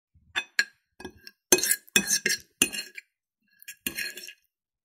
Tiếng Dao Nỉa khi ăn món Tây, món Âu… (Ngắn)
Thể loại: Tiếng ăn uống
Description: Tiếng dao nĩa leng keng, lách cách vang lên trên bàn tiệc sang trọng, âm thanh tinh tế của bữa ăn phong cách Âu. Âm thanh dao nĩa chạm nhẹ vào đĩa sứ, tiếng kéo rê... tạo nên không khí chuẩn nhà hàng Tây. Hiệu ứng âm thanh này gợi cảm giác sang trọng, tinh tế, phù hợp làm sound effect, edit video về ẩm thực, bàn tiệc, nhà hàng châu Âu.
tieng-dao-nia-khi-an-mon-tay-mon-au-ngan-www_tiengdong_com.mp3